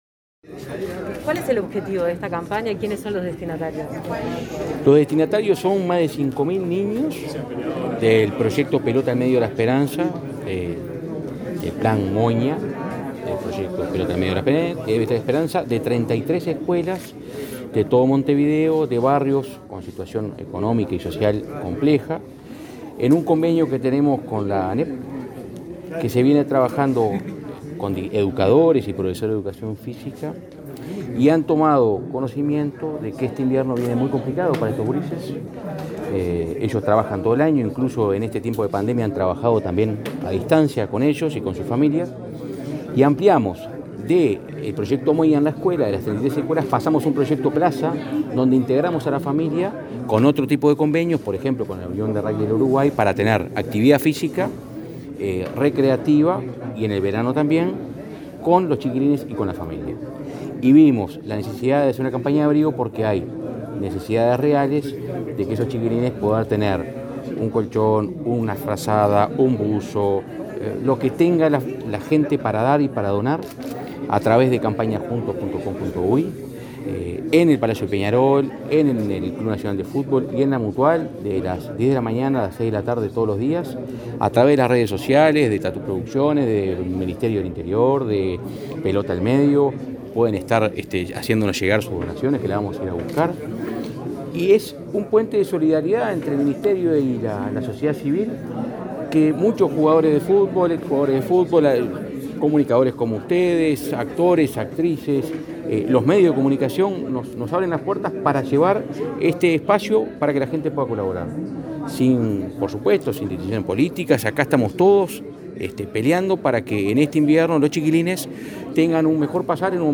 El director de Convivencia y Seguridad Ciudadana del Ministerio del Interior, Santiago González, destacó en el lanzamiento de la campaña “Juntos ayudemos a abrigar” que el proyecto Moña trabaja con escolares de barrios en situación de vulnerabilidad. En este contexto, agregó que el ministerio instauró el proyecto Plaza para integrar a estas familias en un espacio común, con actividades lúdicas y recreativas.